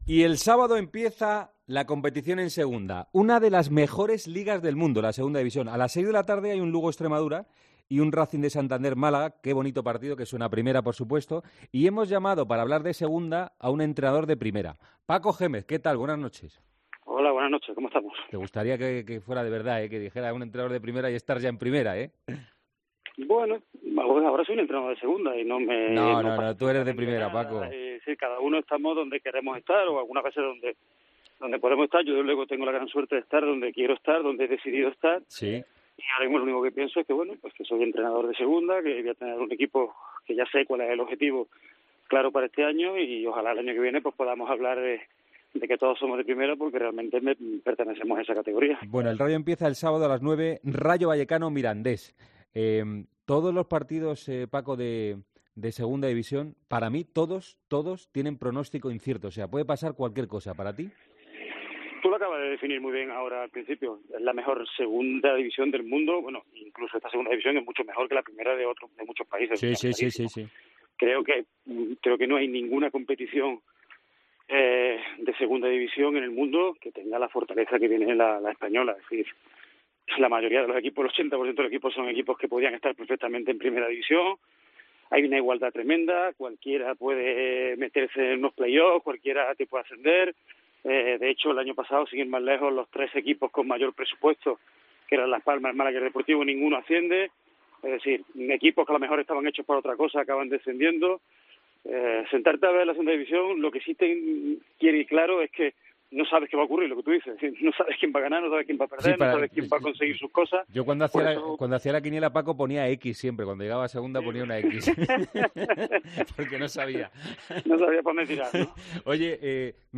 El entrenador del Rayo Vallecano Paco Jémez pasó este jueves por los micrófonos de ' El Partidazo de COPE' para analizar la que será su segunda temporada en Vallecas en su segunda etapa en el conjunto madrileño.